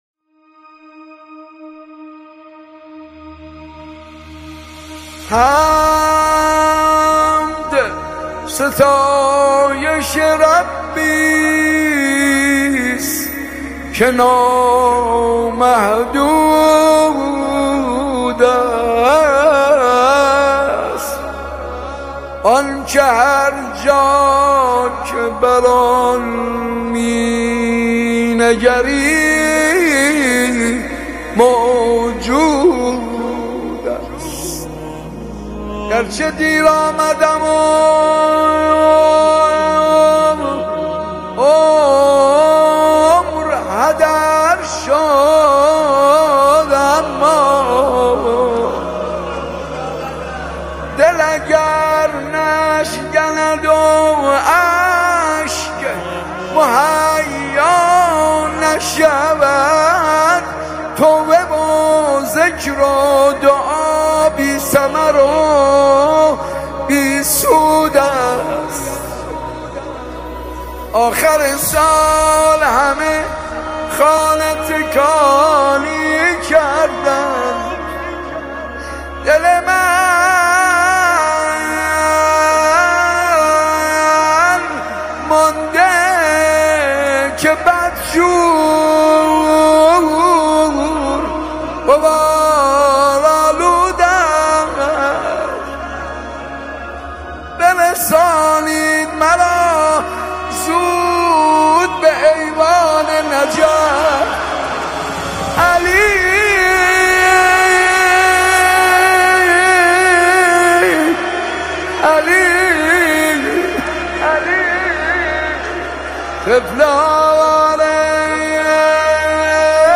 اثری دل‌انگیز و معنویبرای ماه مبارک رمضان است
مناجات ویژه ماه رمضان